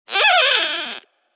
squeak.wav